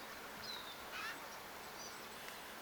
tuollainen järripeipon ääni
tuollainen_jarripeipon_aani.mp3